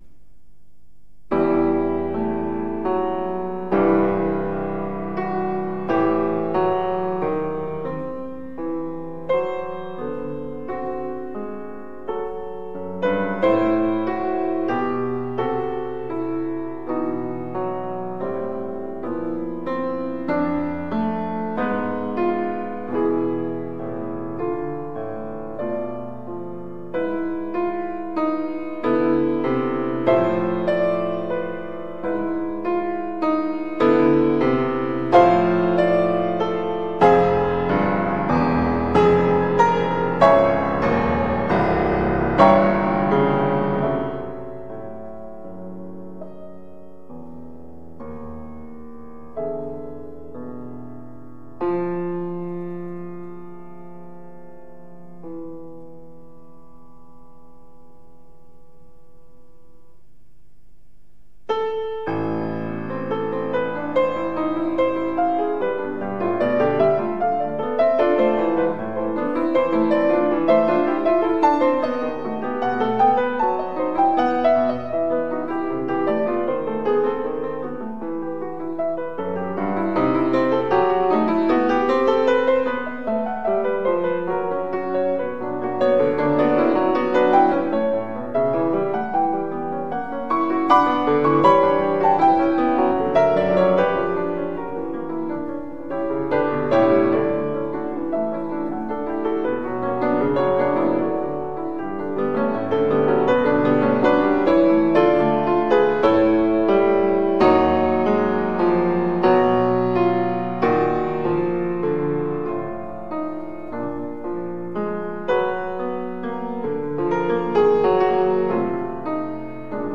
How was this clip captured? Utrecht Conservatory concert hall live recording